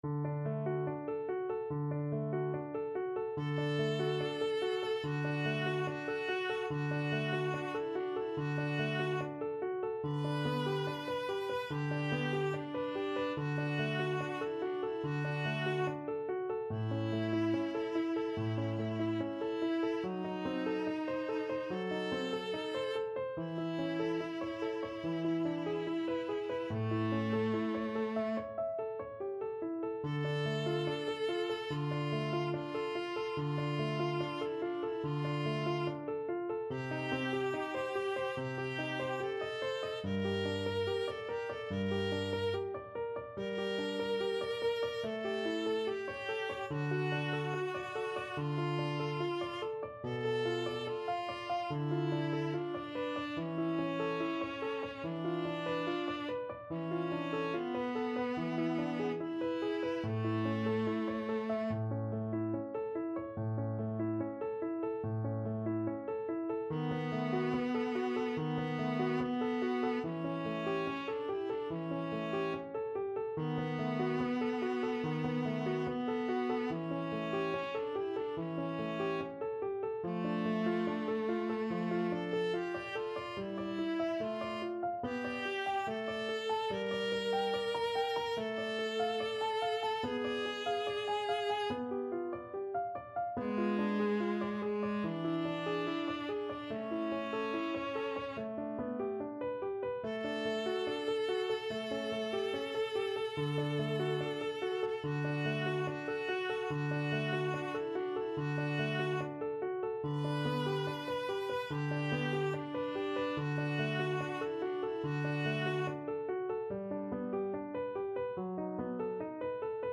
Viola 1Viola 2
Andante =72